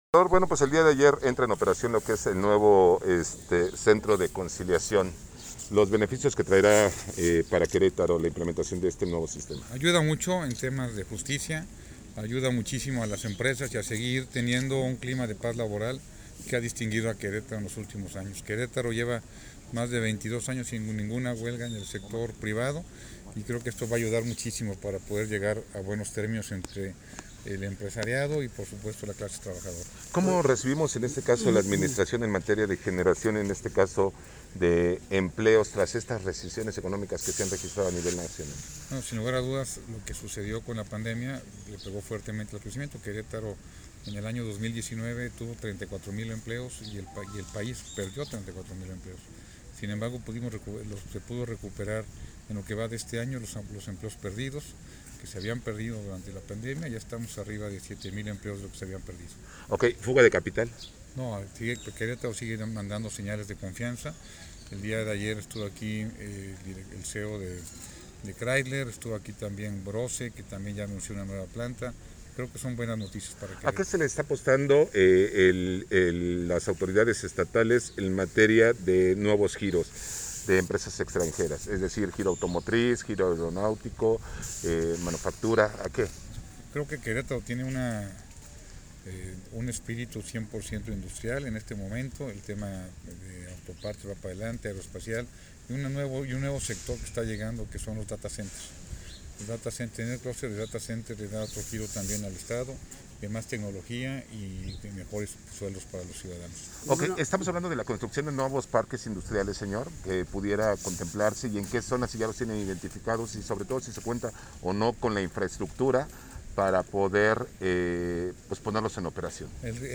Entrevista Mauricio Kuri